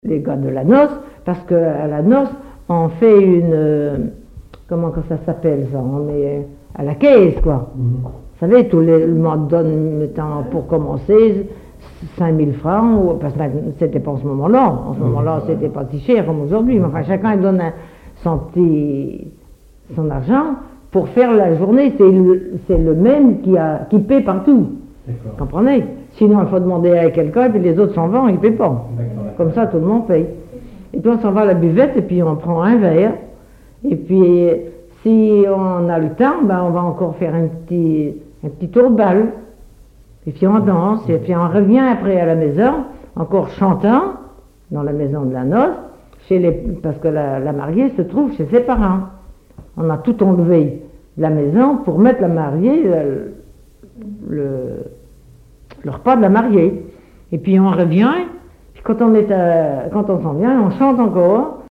collecte en Vendée
Catégorie Témoignage